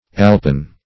Search Result for " alpen" : The Collaborative International Dictionary of English v.0.48: Alpen \Al"pen\, a. Of or pertaining to the Alps.